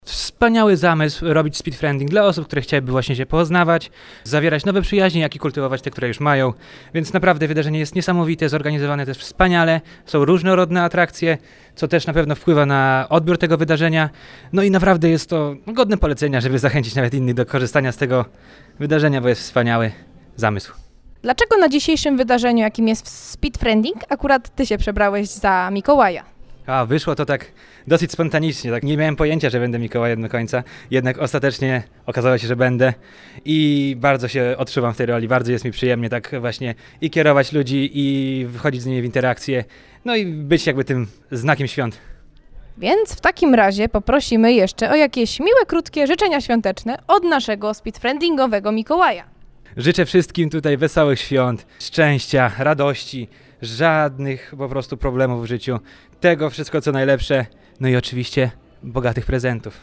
Udało nam się porozmawiać także… z Mikołajem.